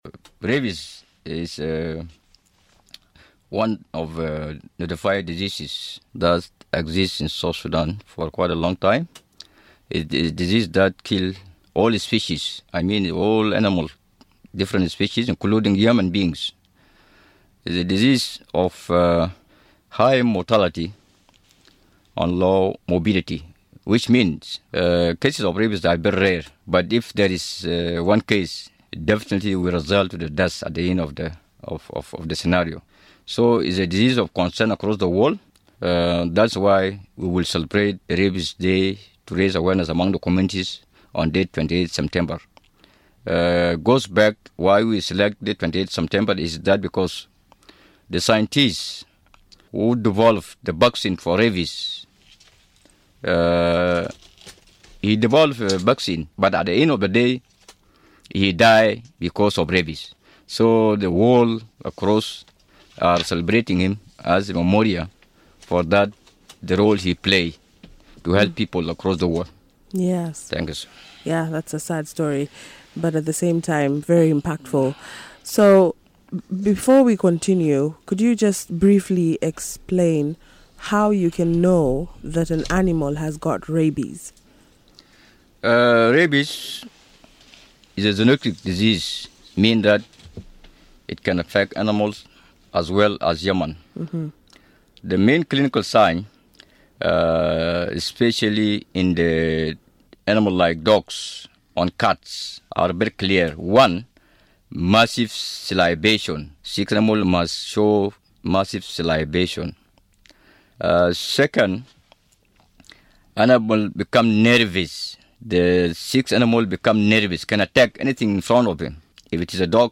This will be marked with rabies vaccination campaigns, animal welfare and public health messaging. Listen to the full discussion